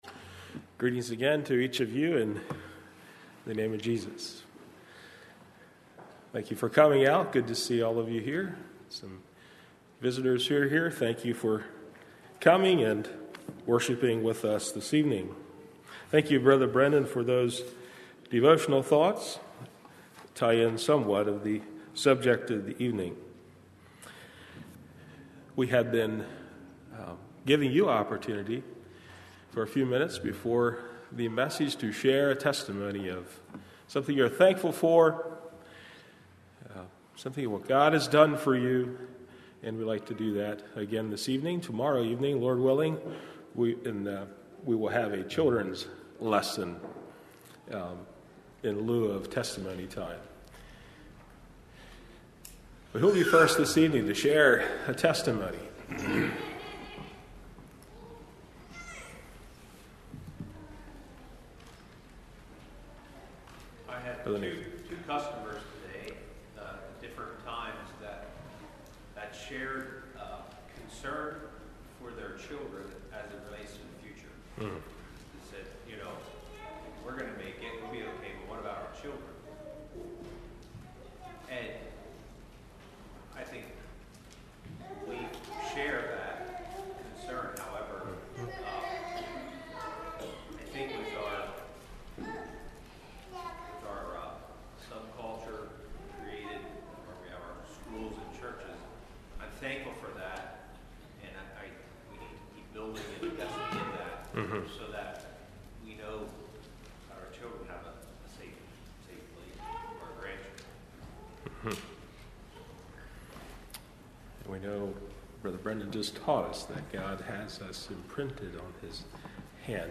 Congregation: Swatara